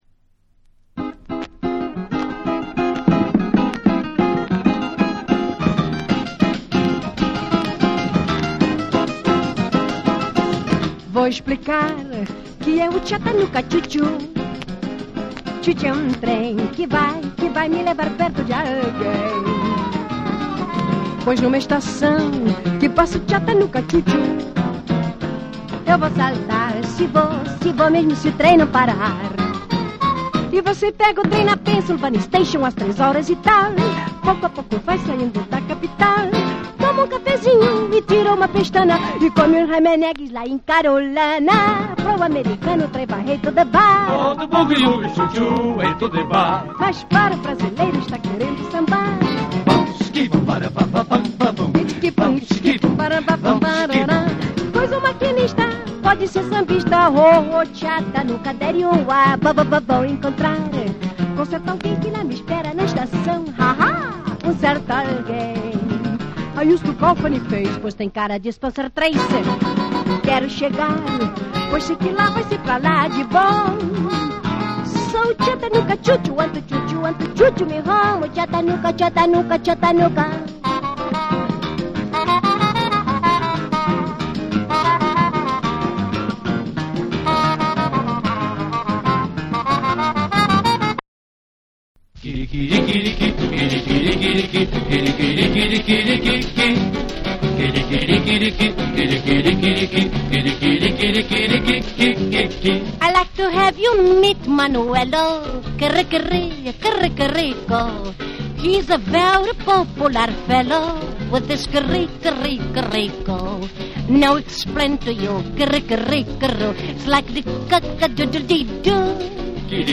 ポルトガル生まれのブラジル人シンガー
WORLD